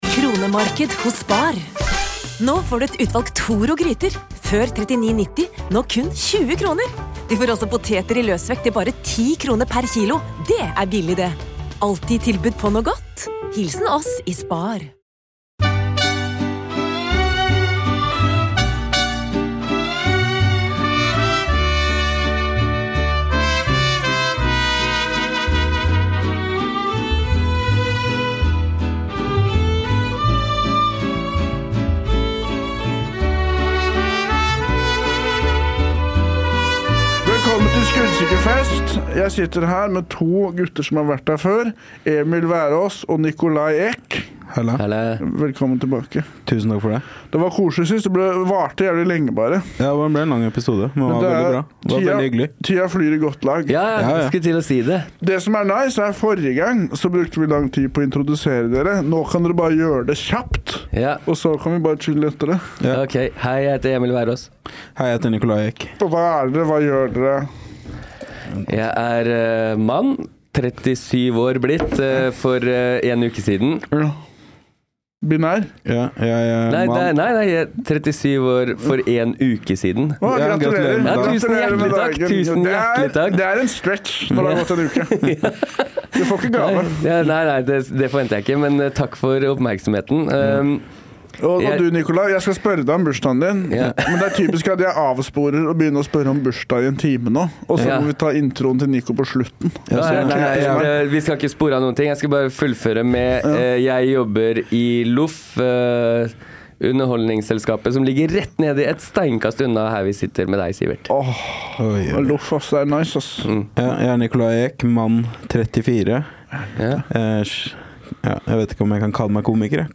Hver fredag (og innimellom tirsdag med gjest) møtes de i studio for å hylle middelmådigheten.
… continue reading 91 episodes # Komedie # Juicy Producy og Bauer Media